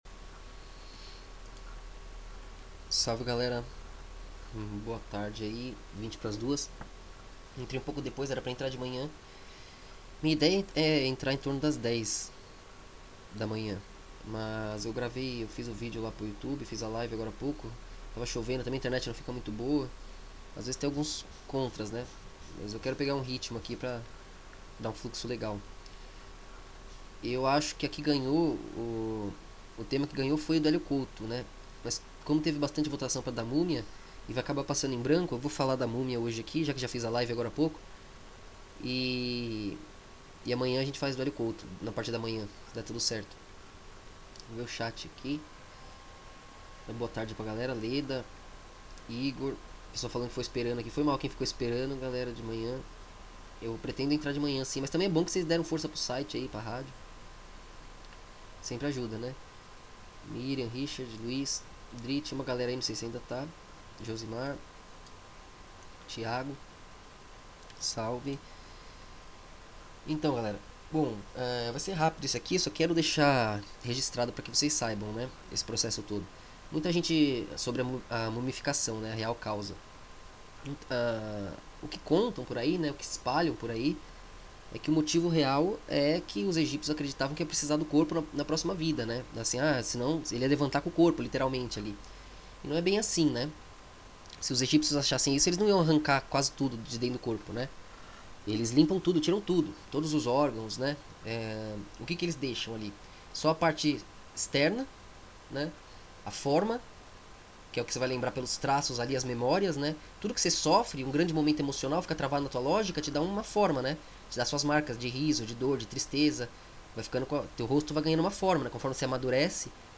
Ao vivo: Qual o real motivo da mumificação?